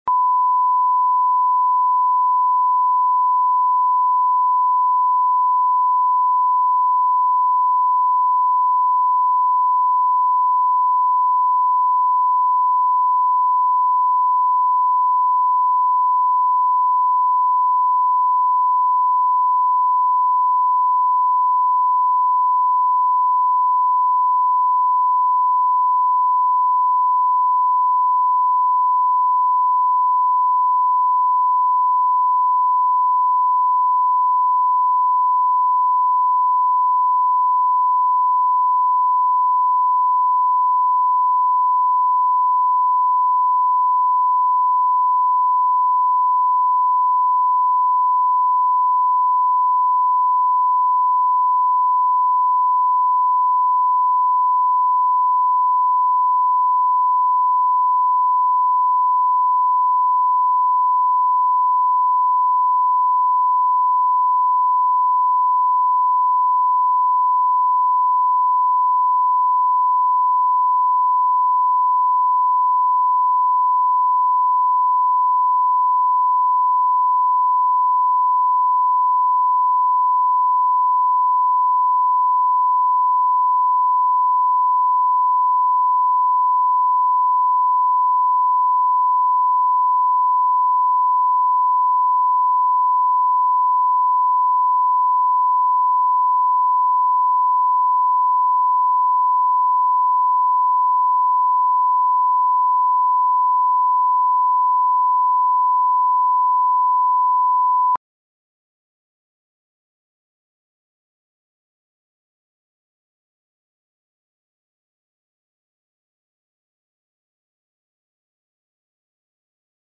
Аудиокнига Бумажное счастье | Библиотека аудиокниг
Прослушать и бесплатно скачать фрагмент аудиокниги